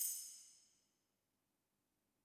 JJPercussion (10).wav